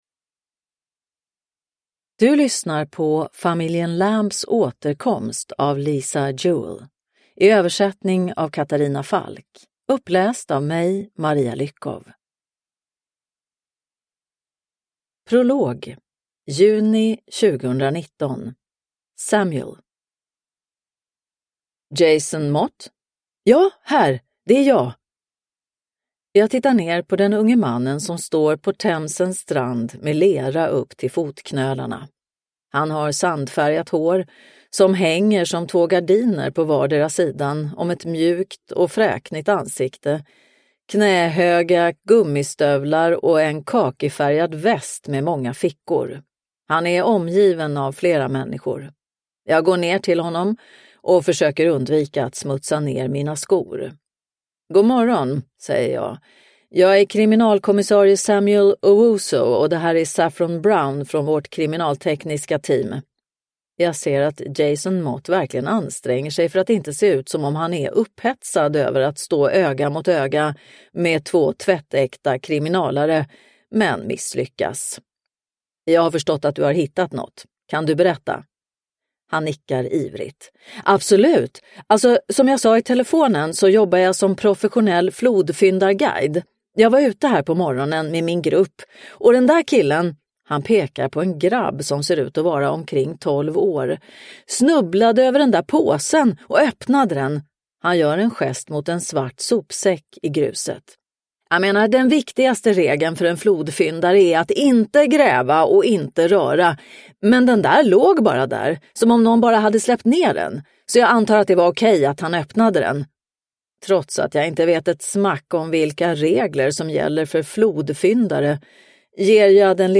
Familjen Lambs återkomst – Ljudbok – Laddas ner